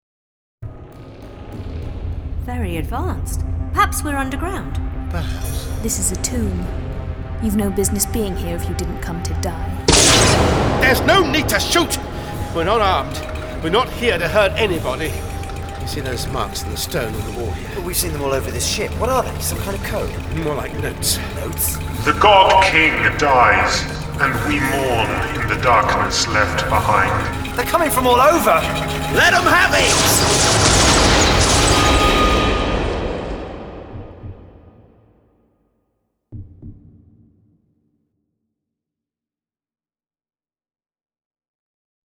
Starring Peter Davison Sarah Sutton